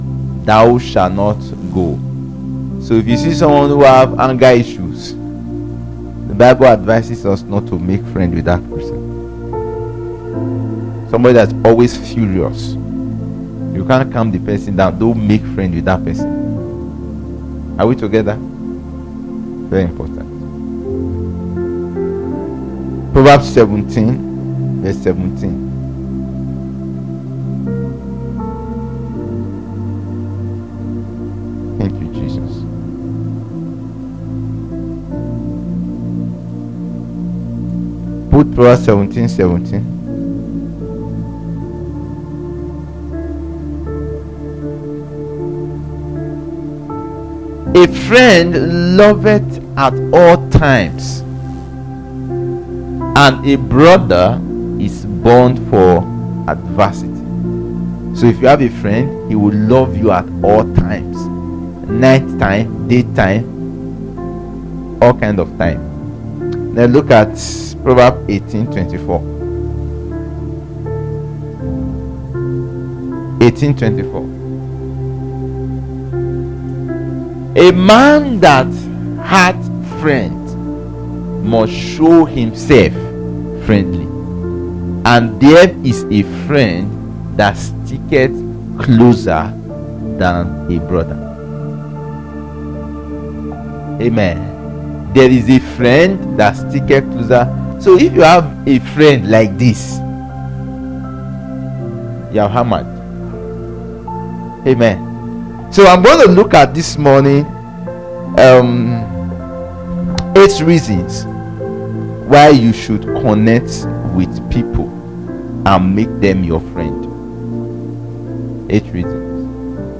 2024 How To Make Friends Preacher